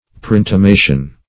Search Result for " preintimation" : The Collaborative International Dictionary of English v.0.48: Preintimation \Pre*in`ti*ma"tion\, n. Previous intimation; a suggestion beforehand.